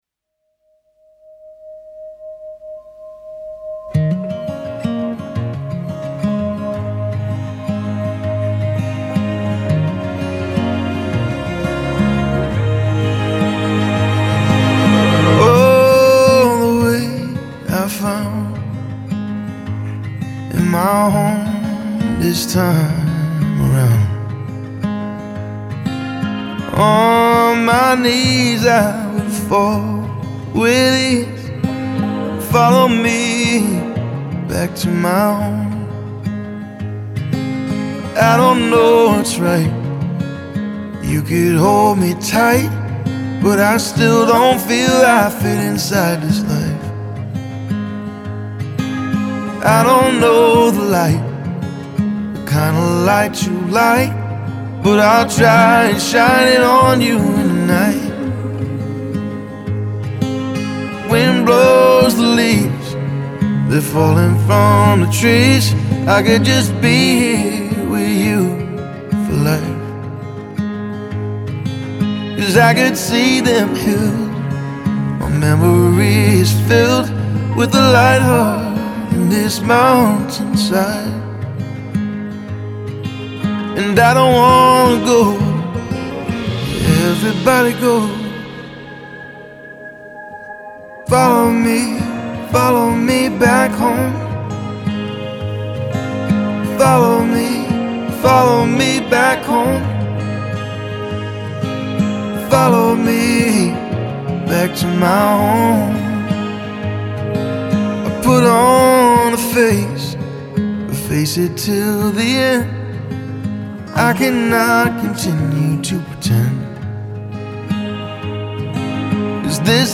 Part crooner, part troubadour, all storyteller.